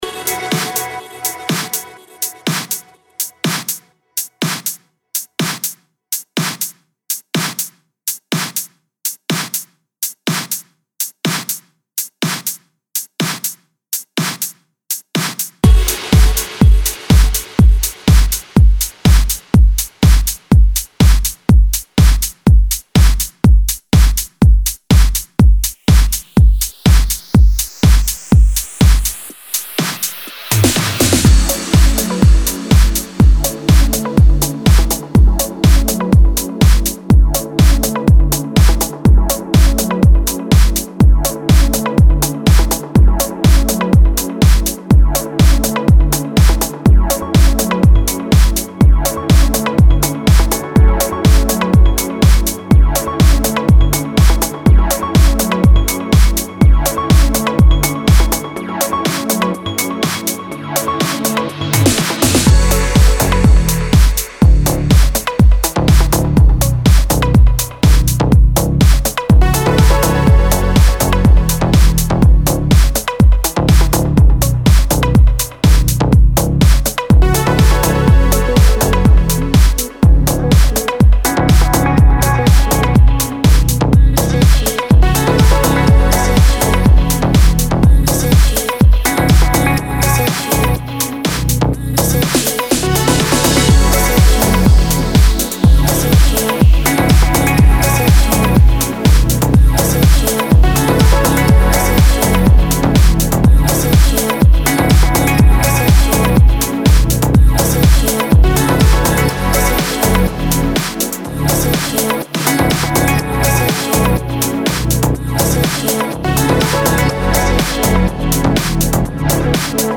Всем привет,зацените Progressive house.